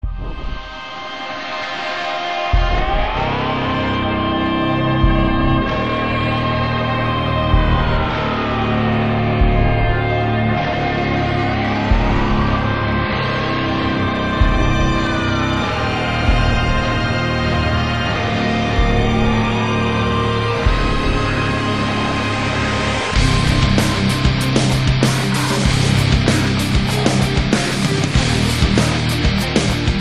offers a grindingly metallic riff-rocker
opera-like voice and sound